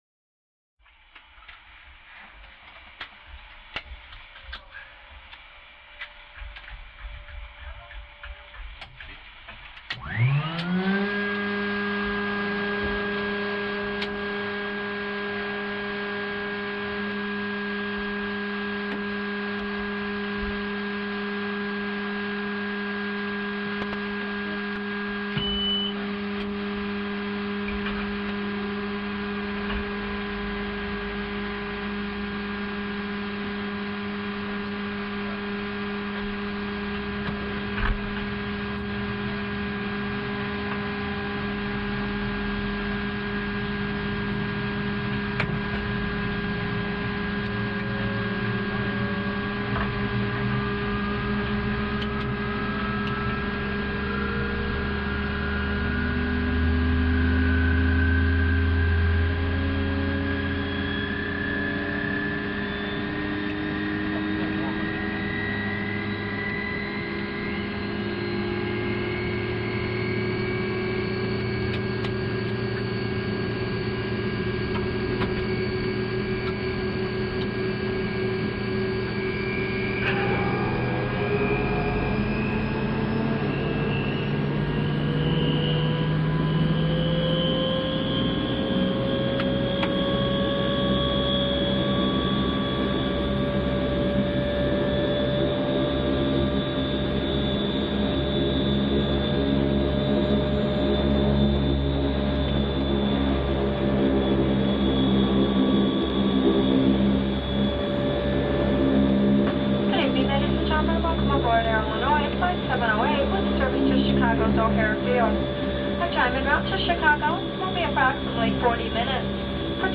AIRCRAFT PROP TWIN TURBO: INT: Start APU, warm up, taxi, P.A. announcements.